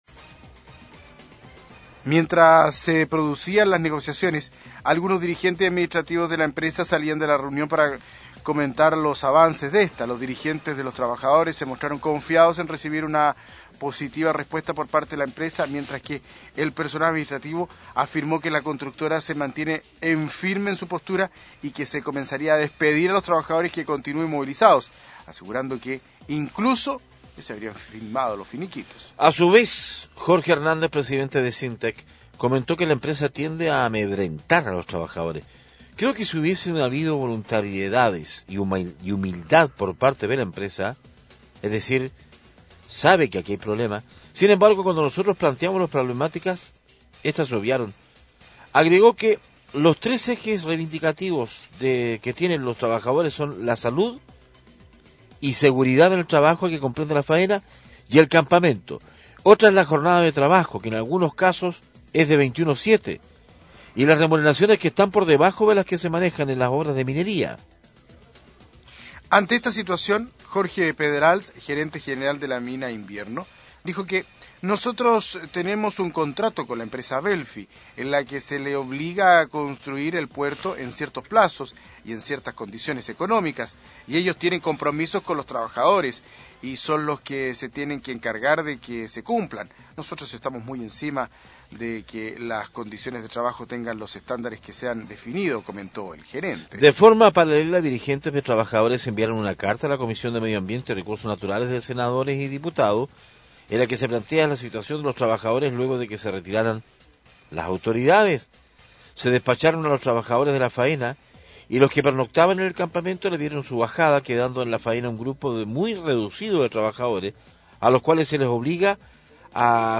Entrevistas de Pingüino Radio - Diario El Pingüino - Punta Arenas, Chile
José Saldivia, concejal por Punta Arenas